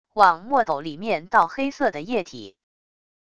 往墨斗里面倒黑色的液体wav音频